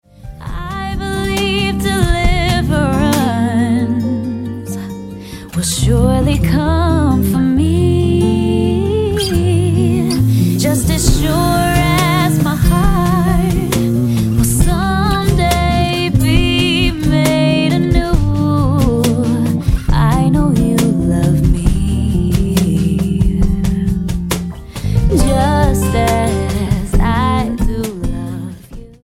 STYLE: Gospel
slick jazz exposition